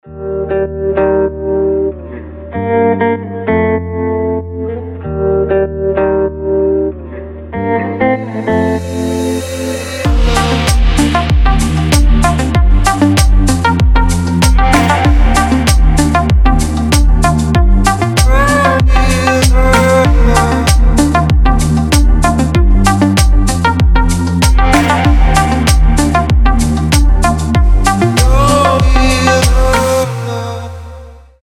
красивые
deep house
мелодичные
chillout
нарастающие